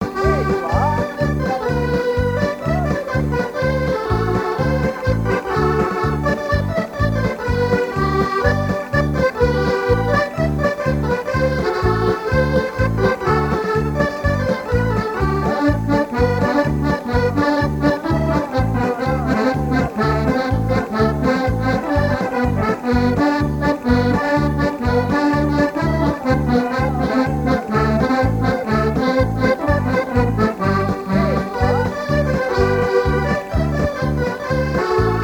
Chants brefs - A danser
danse : polka
circonstance : bal, dancerie
répertoire de bal du 3ième âge à Sion Saint-Hilaire-de-Riez